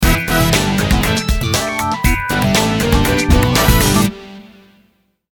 Verizon_Default_Ringer_.mp3